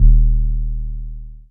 808 (KeepItEasy).wav